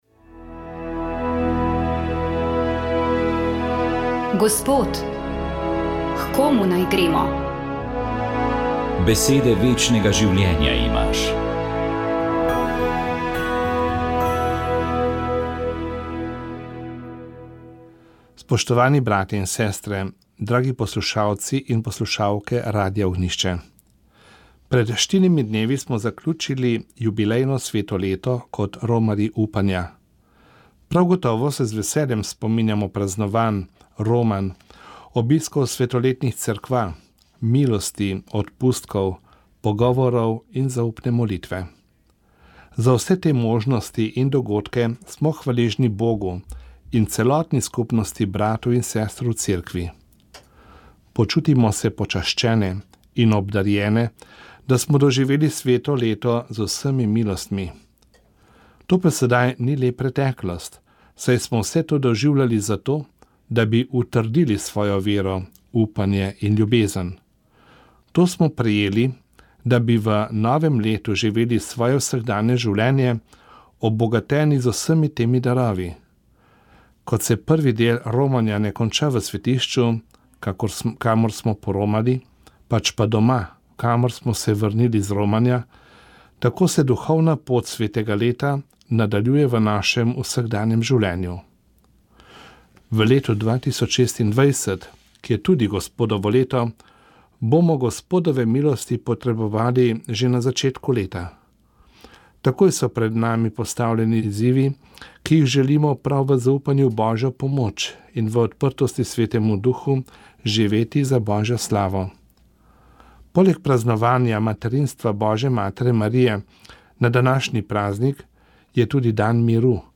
Škof poudari povezanost z Jezusom Kristusom, ki izpolnjuje starozavezne obljube, ter nujnost okrepljenega zaupanja v Božje obljube.